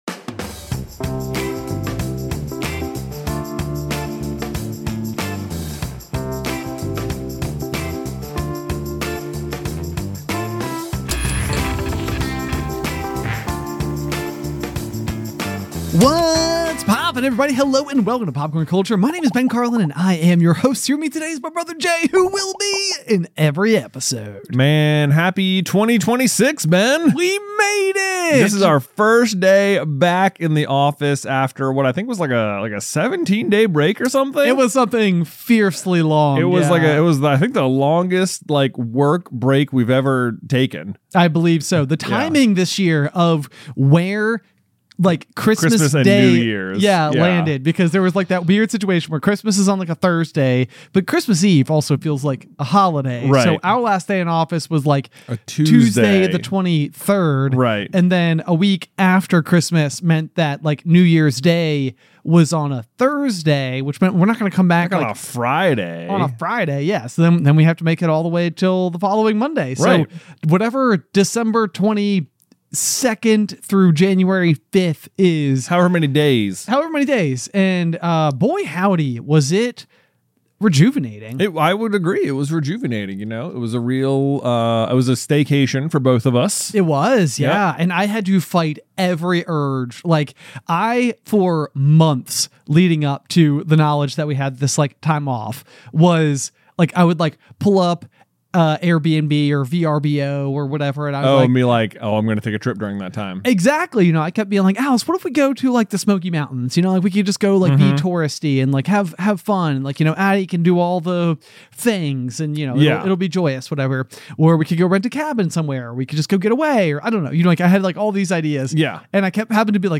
They explore the themes of cult cinema, the impact of dubbing on cultural representation, and the absurdity of the film's plot and fight scenes. The conversation is filled with humor, insightful commentary, and a critical look a…